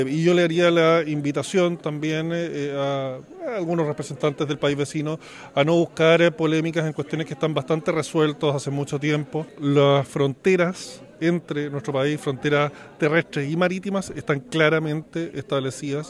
Desde el Partido Republcano, el diputado Luis Fernando Sánchez hizo un llamado a representantes del país vecino a no polemizar sobre materias zanjadas hace ya varias décadas.